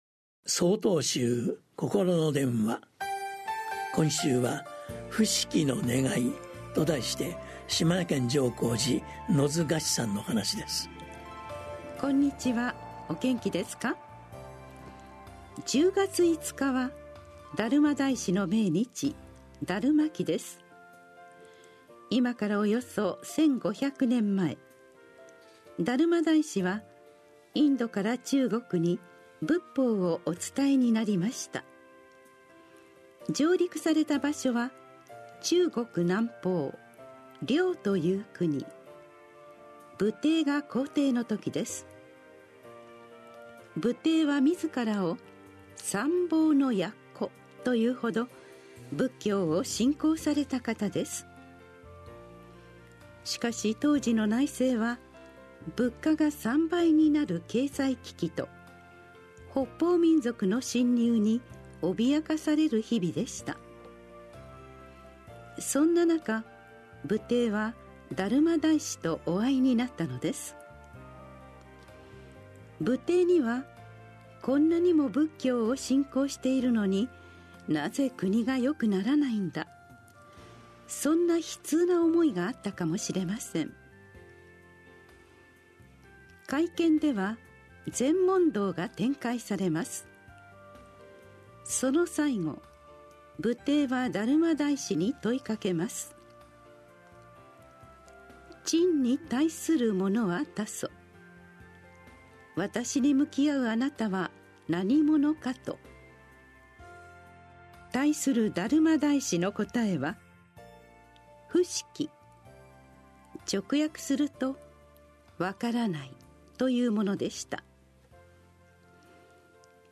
心の電話（テレホン法話）9/30公開『不識のねがい』 | 曹洞宗 曹洞禅ネット SOTOZEN-NET 公式ページ